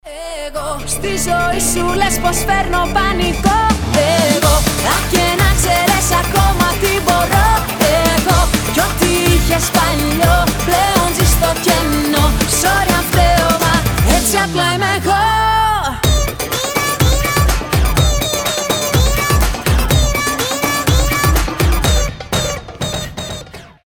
• Качество: 320, Stereo
зажигательные
женский голос
Pop Rock
динамичные
Зажигательная поп-песня на греческом